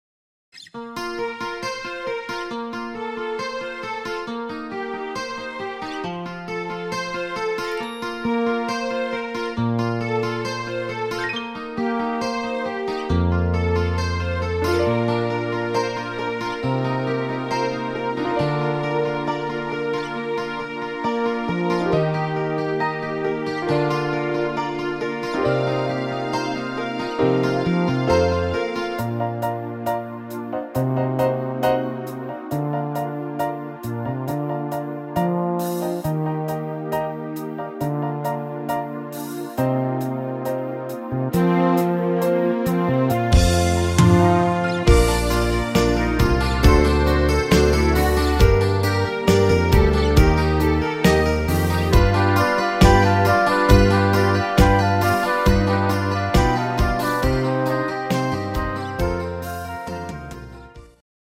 Rhythmus  Slow
Art  Pop, Englisch, Oldies